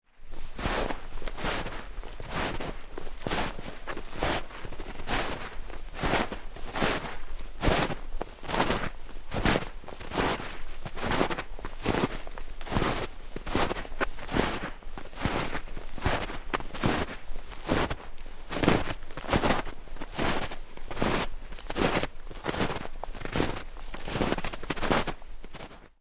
Звуки шагов по снегу
Кто-то пробирается сквозь снег